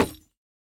Minecraft Version Minecraft Version snapshot Latest Release | Latest Snapshot snapshot / assets / minecraft / sounds / block / bamboo_wood_hanging_sign / break2.ogg Compare With Compare With Latest Release | Latest Snapshot